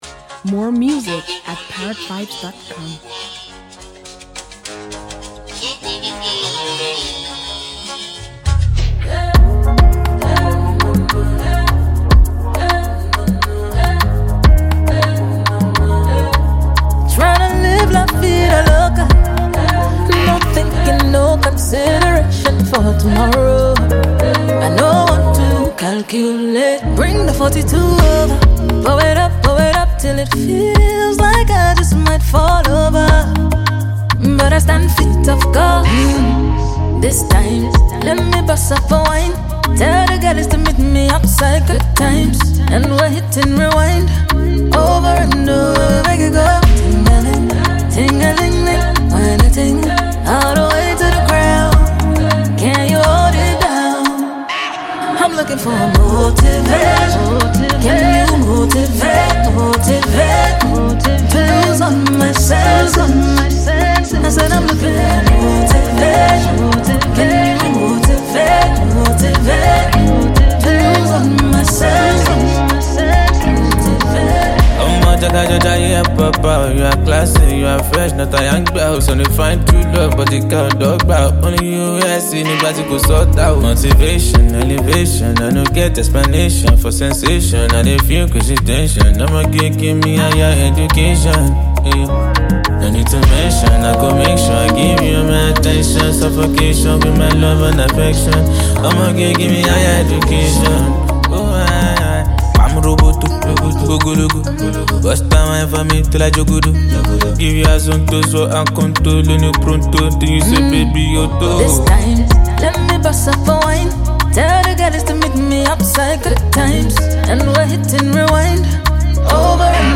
Impressively proficient Nigerian singer and songwriter
Nigerian rap legend